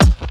• 00s Snappy Rap Bass Drum One Shot A# Key 655.wav
Royality free steel kick drum sample tuned to the A# note. Loudest frequency: 720Hz